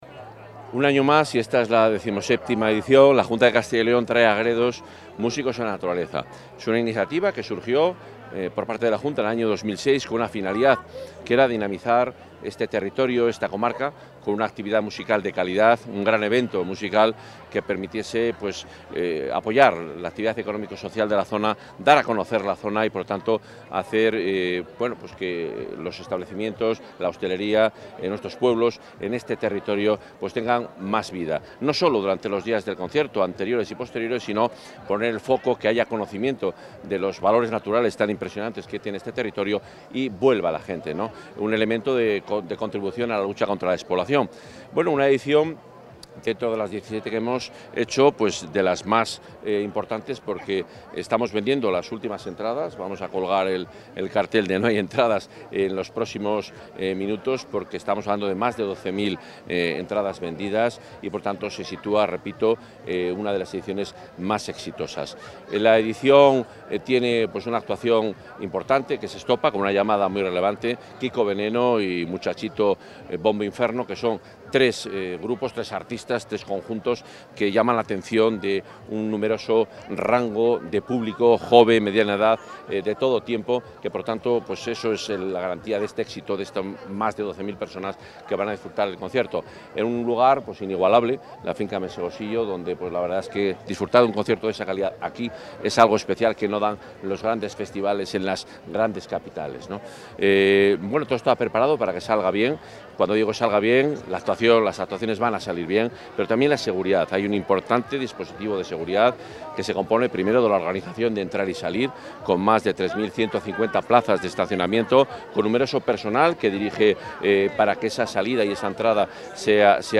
Declaraciones del consejero.